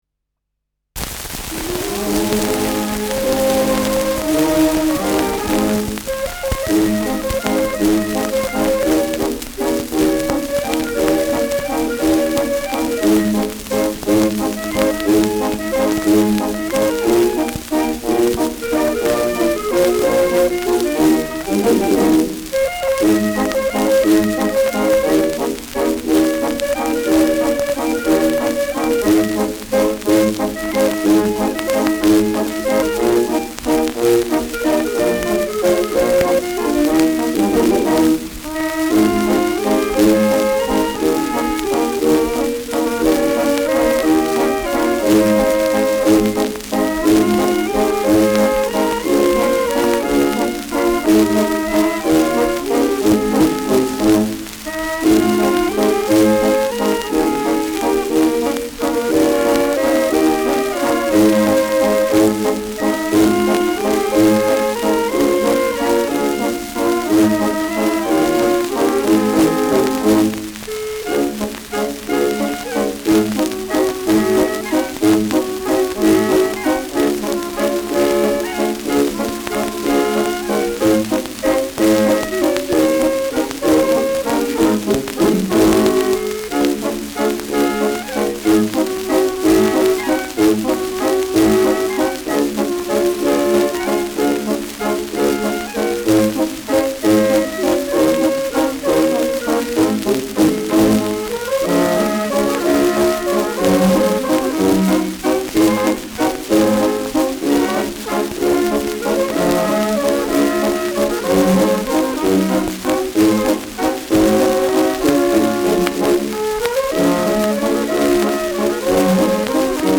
Schellackplatte
Tonrille: graue Rillen : leichte Kratzer durchgängig
präsentes Rauschen : präsentes Knistern : abgespielt : gelegentliches Knistern : leiert
Kapelle Jais (Interpretation)
[München] (Aufnahmeort)